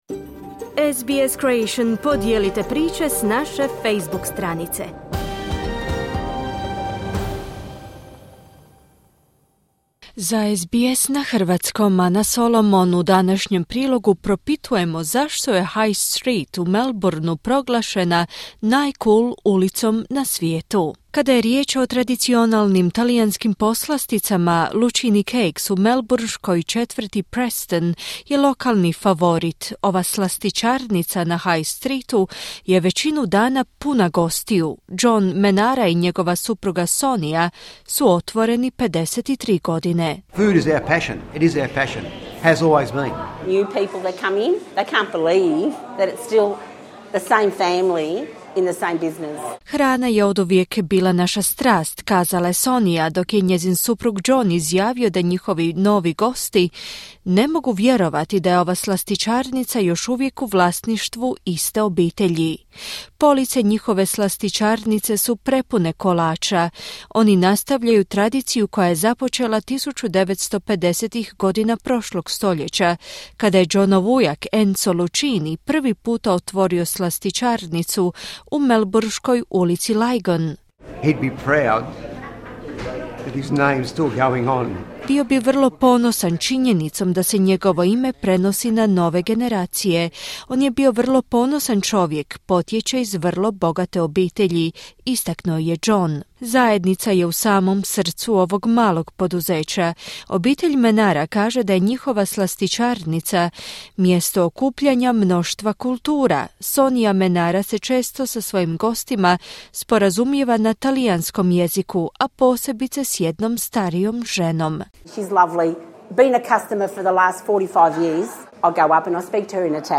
Restorani s raznolikom ponudom, skriveni kafići i sjajni butici su dijelom bogate ponude u jednoj australskoj ulici koja je proglašena najcool ulicom na svijetu. Obilježavajući 50. godišnjicu SBS-a, razgovarali smo s nekolicinom trgovaca u toj ulici multikulturalnog podrijetla.